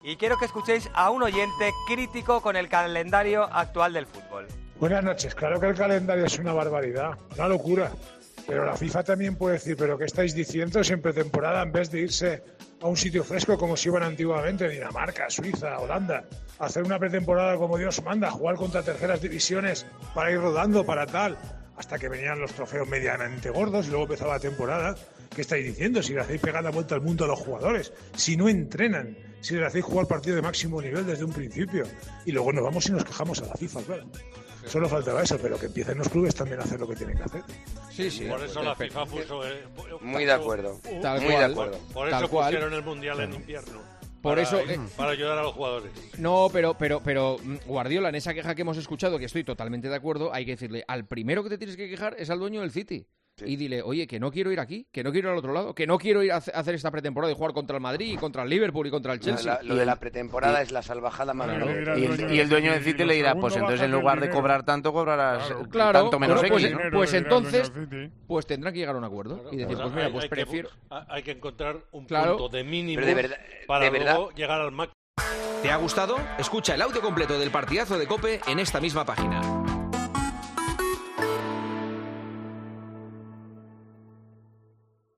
Uno de los primeros en hablar sobre lo ocurrido en este parón de selecciones fue el entrenador catalán, en Tiempo de Juego escuchamos esas palabras y el presentador las comentó